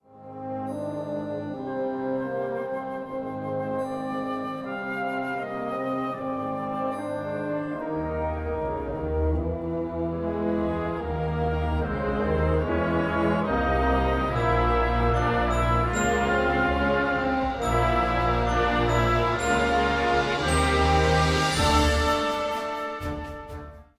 Besetzung: Blasorchester
filled with cyclonic energy!